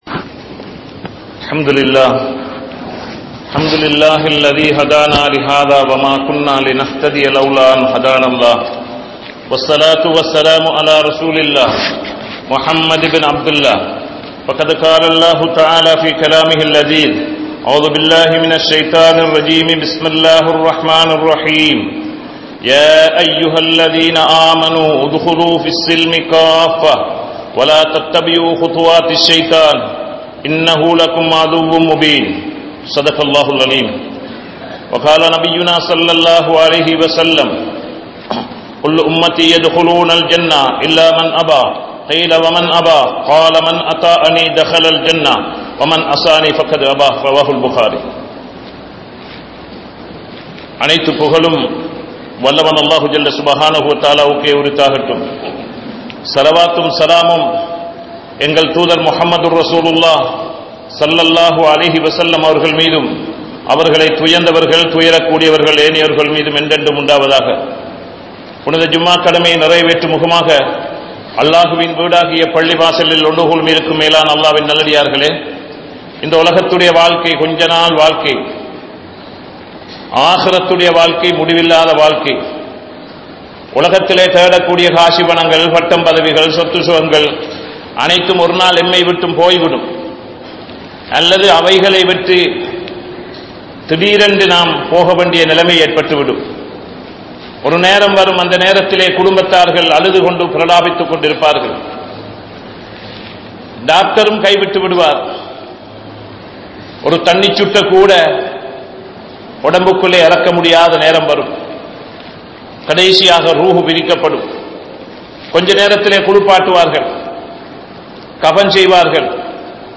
Thirumanaththai Thuspirayoaham Seiyaatheerhal (திருமணத்தை துஷ்பிரயோகம் செய்யாதீர்கள்) | Audio Bayans | All Ceylon Muslim Youth Community | Addalaichenai
Theliyagonna Jumua Masjidh